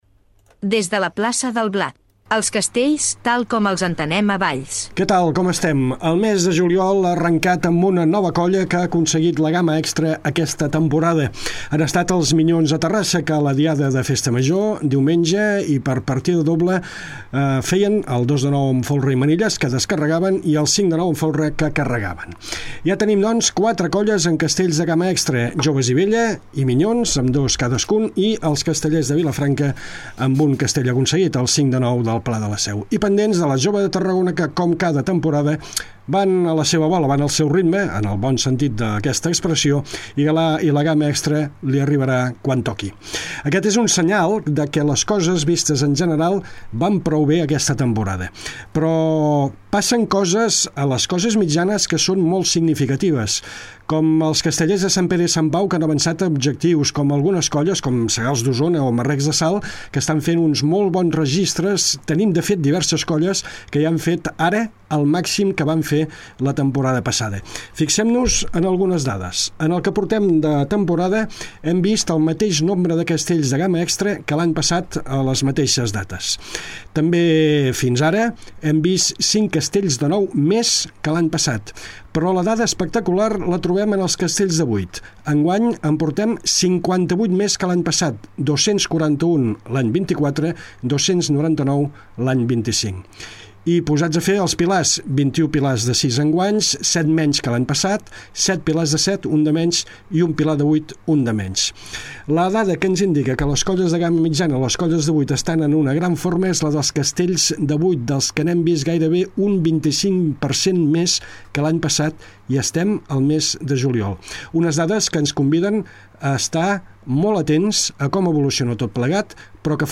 Quarta edició de Des de la Plaça del Blat, el programa que explica els castells tal com els entenem a Valls. Tertúlia